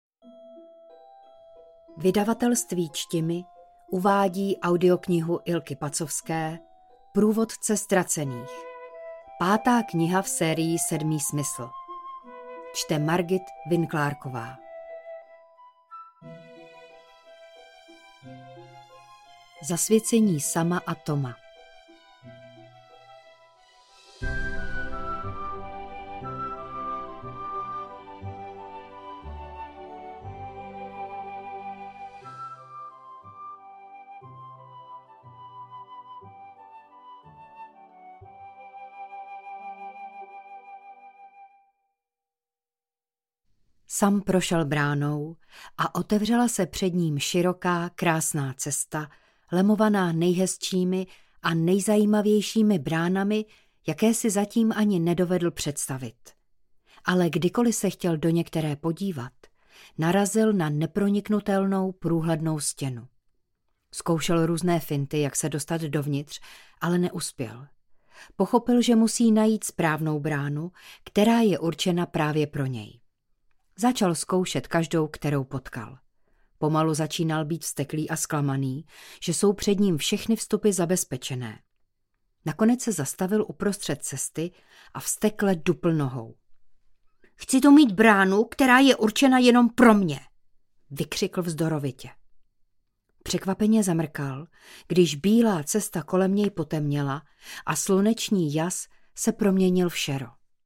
Kategorie: Dobrodružné, Fantasy